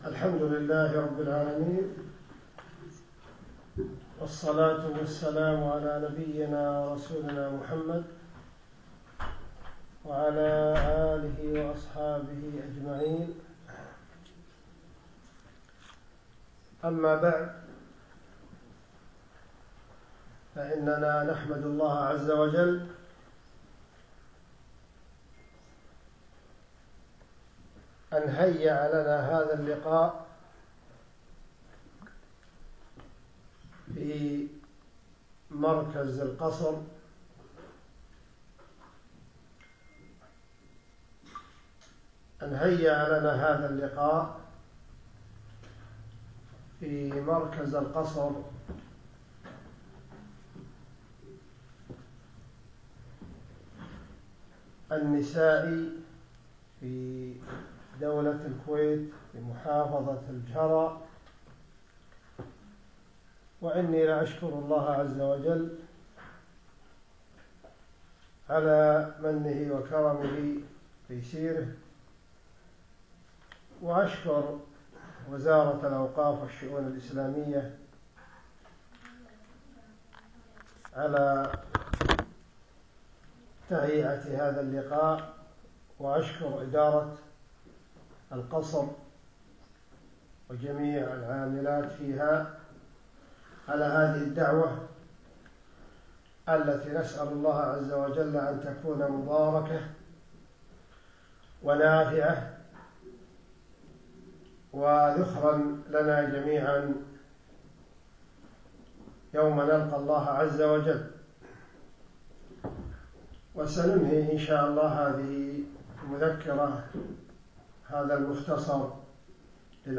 يوم الأثنين 6 2 2017 في مركز القصر نساء مسائي الجهراء